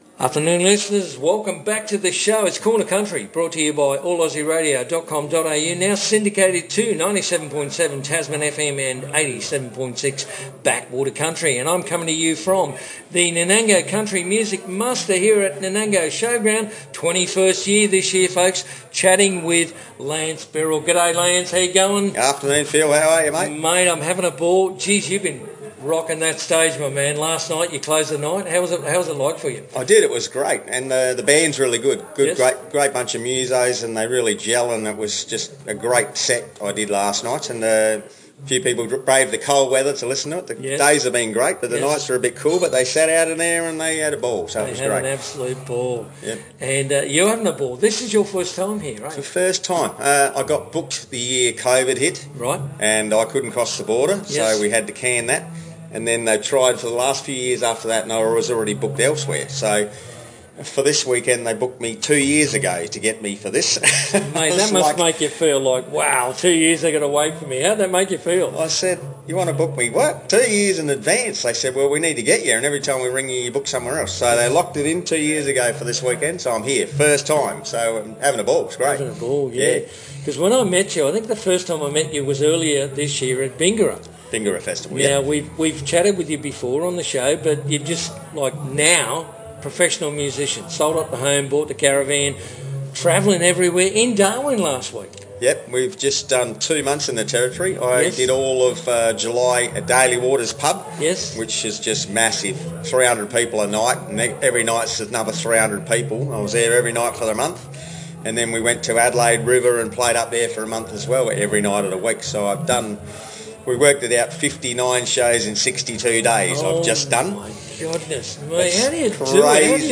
Interview
Nanango 2024